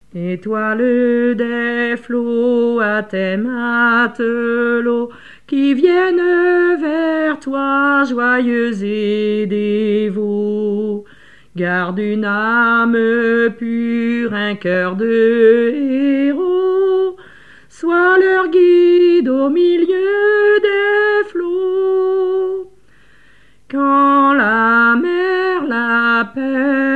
Cantiques
légendes locales, expressions en patois et chansons
Pièce musicale inédite